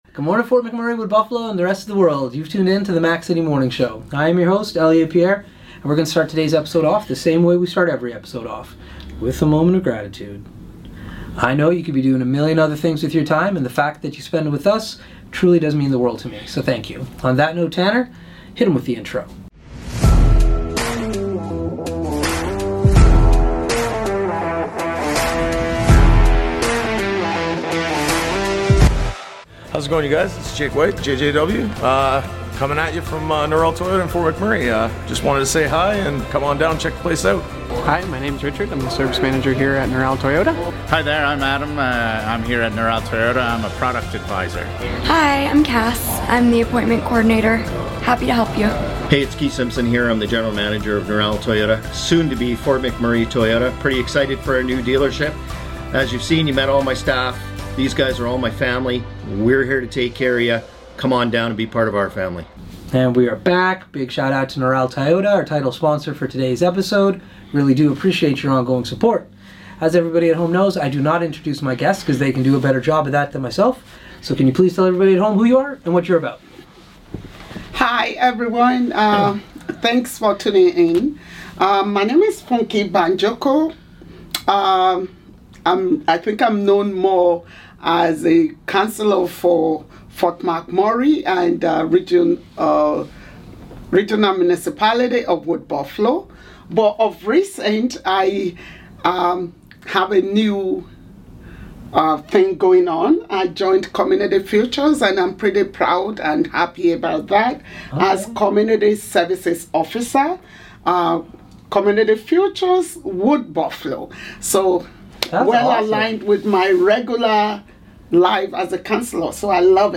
We are excited to welcome back our friend, Local Councillor, Funke Banjoko!